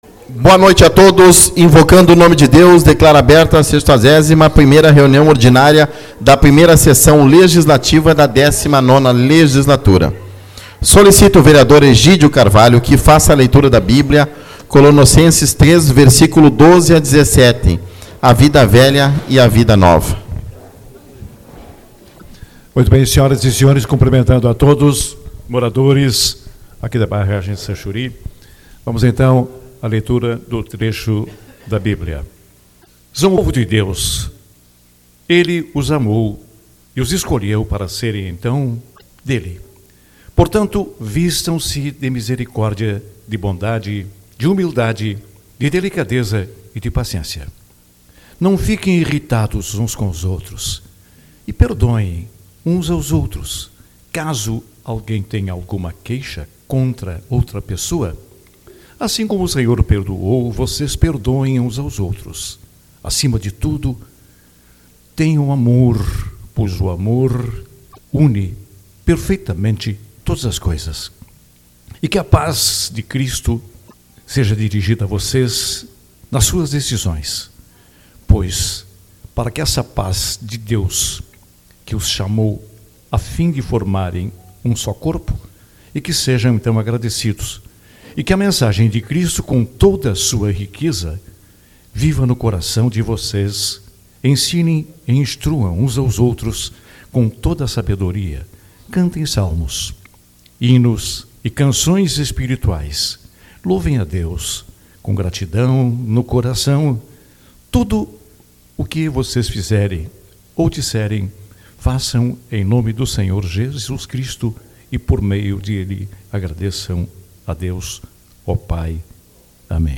Reunião Ordinária-Barragem Sanchuri